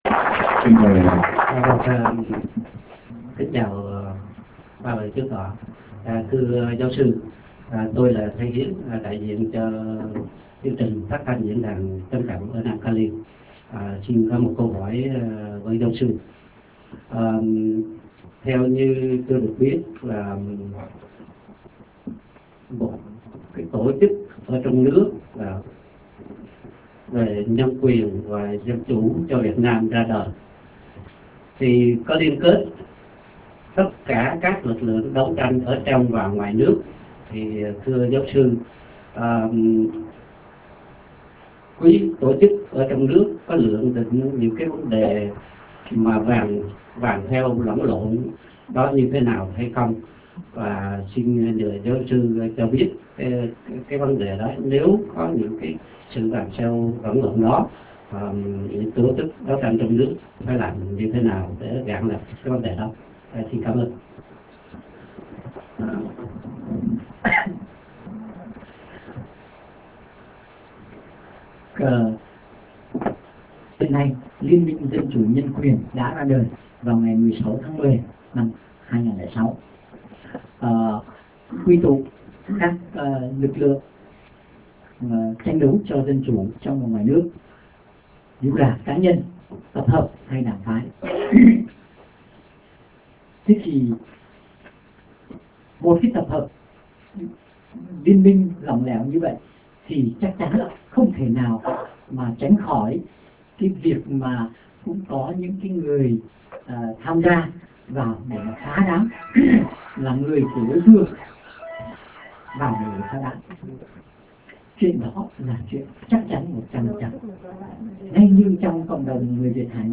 Buổi họp b�o